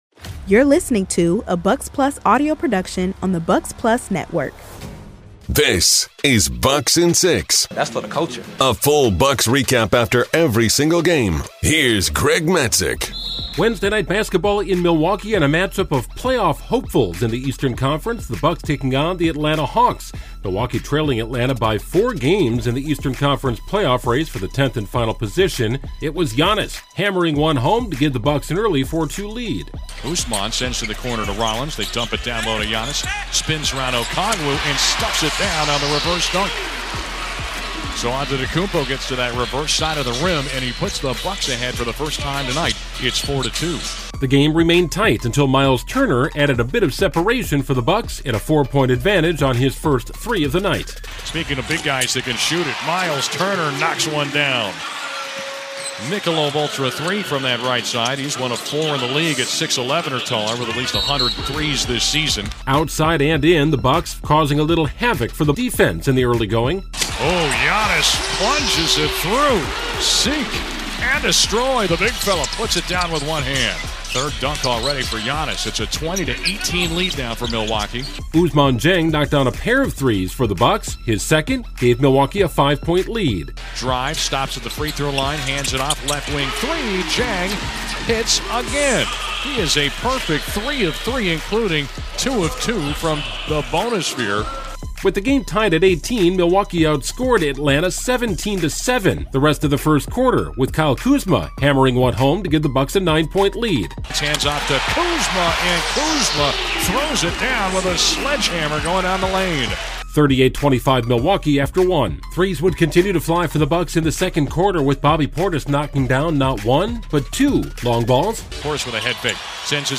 Recapping every game with highlights, players, coaches, and commentary.